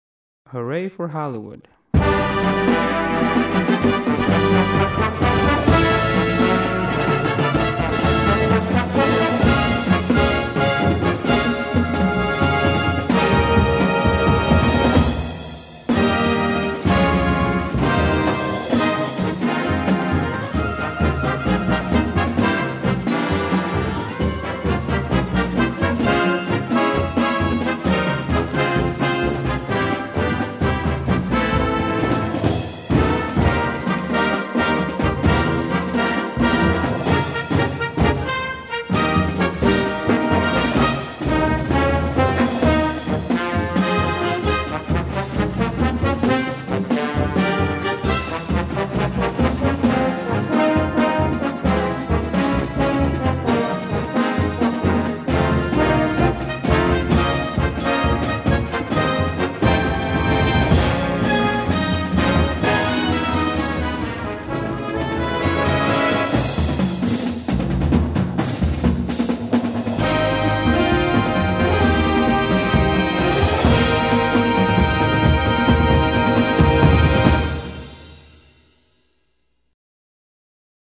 Besetzung: Blasorchester
Einfach zu spielen und fürs Marschbuch geeignet.